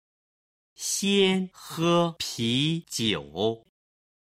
今日の振り返り！中国語発声
先喝啤酒　(xiān hè pí jiǔ)　先にビールを飲む